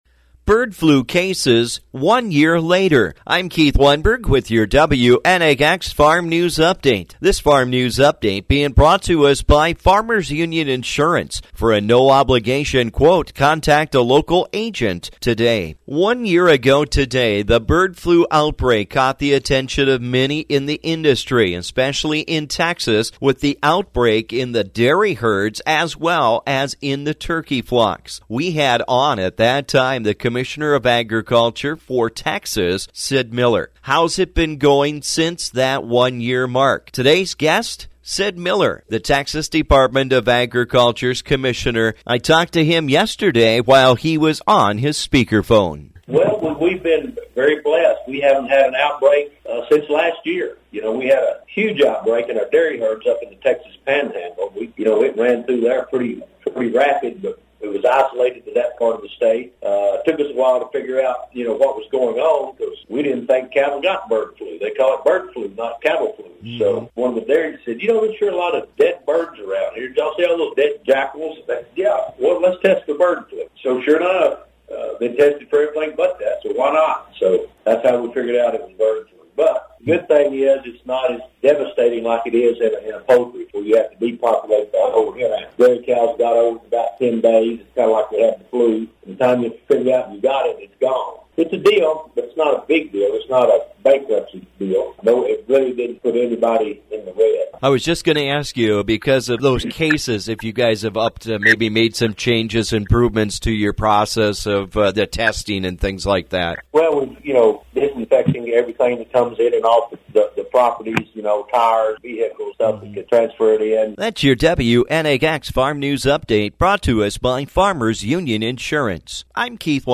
I talked to the Texas Commissioner of Agriculture, Sid Miller